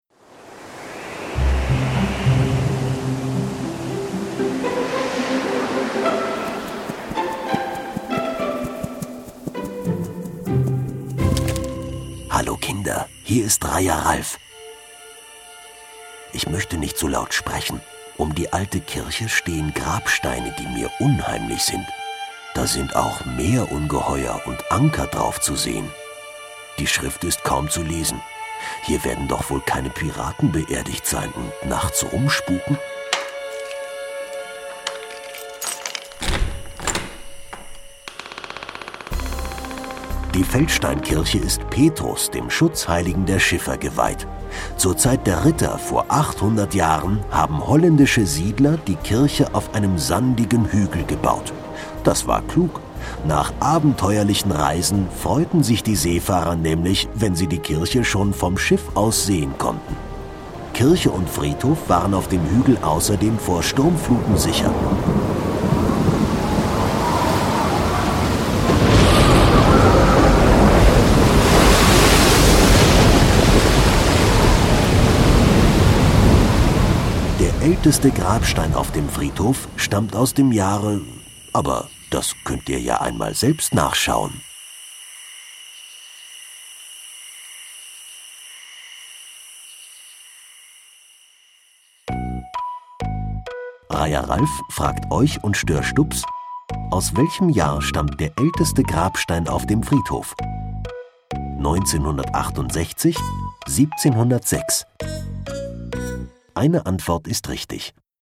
Feldsteinkirche Horst - Kinder-Audio-Guide Oste-Natur-Navi
feldsteinkirche-horst-oste-natur-navi-kinderversion.mp3